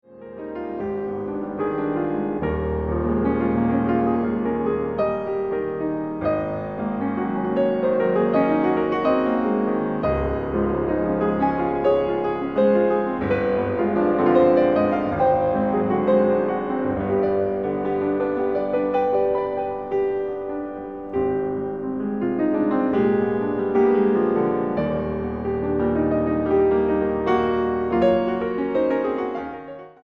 pianista.